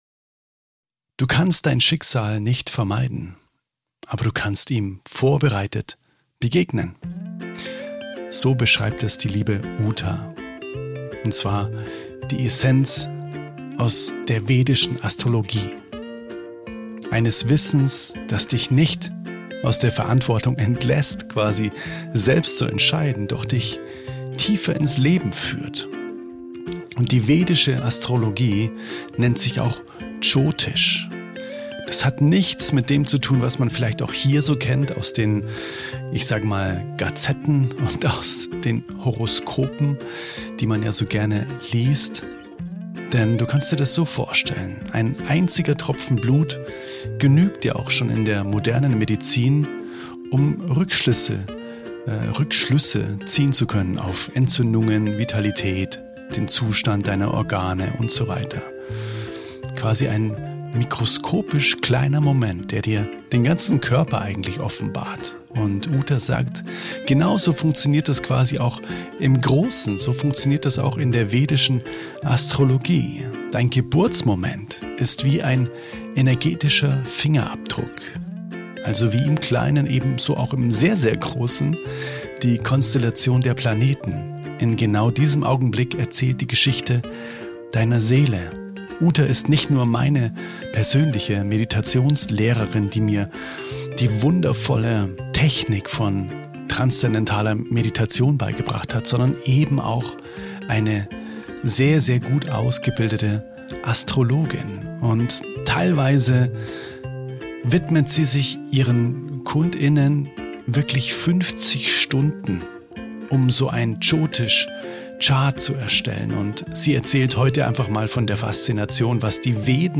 Kosmische Intelligenz – Wie Planetenrhythmen Dein Leben prägen | Interview